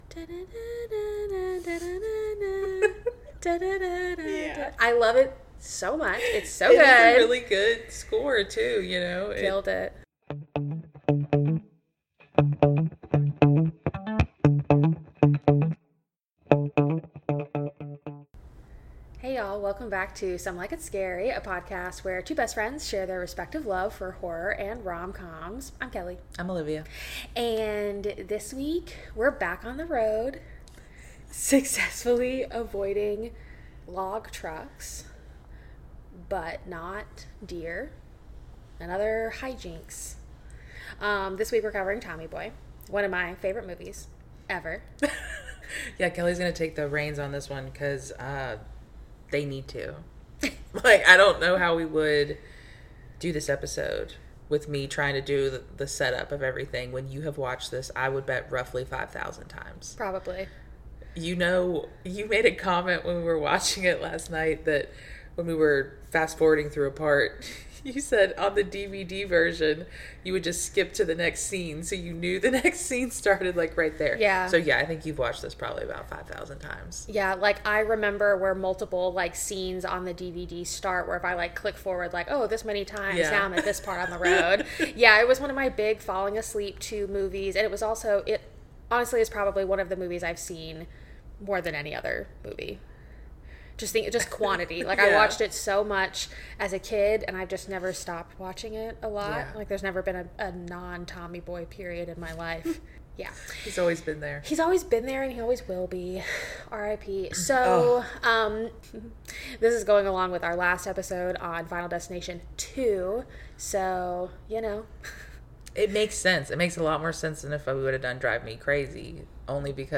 In "Some Like It Scary," two best friends chat romcoms, horror movies, and the tropes of both!